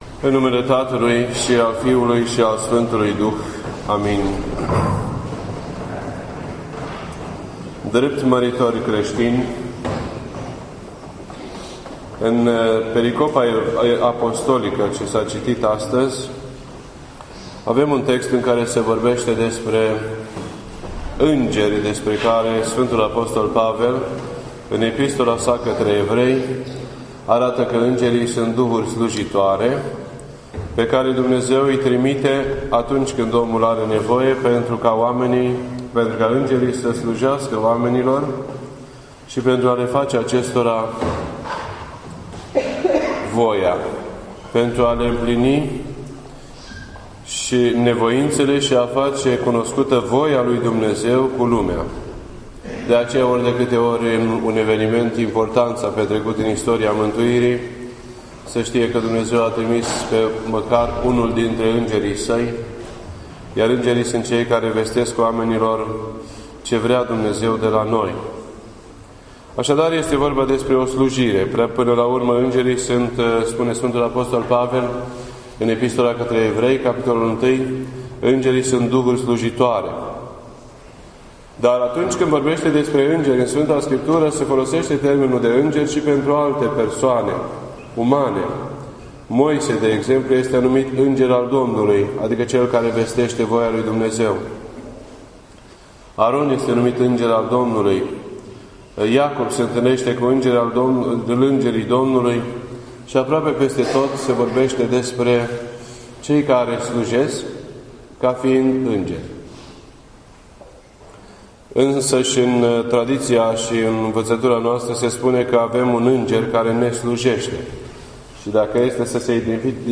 This entry was posted on Sunday, March 31st, 2013 at 8:36 PM and is filed under Predici ortodoxe in format audio.